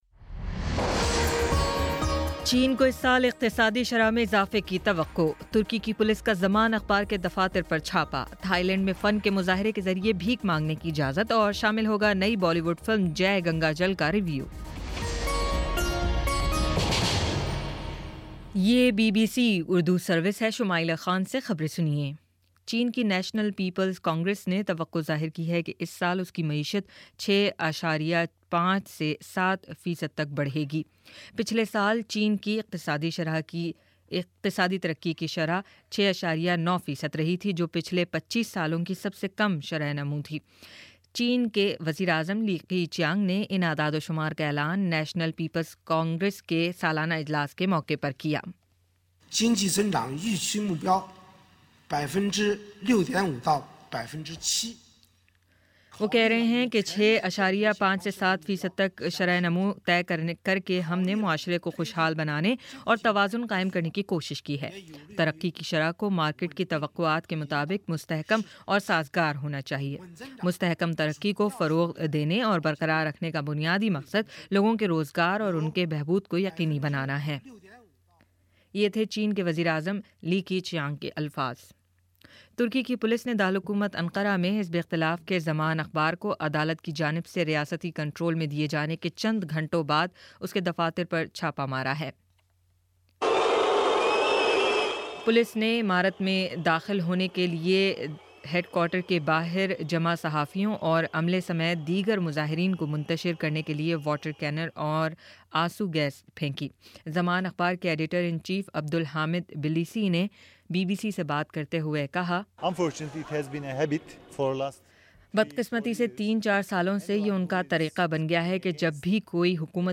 مارچ 05 : شام پانچ بجے کا نیوز بُلیٹن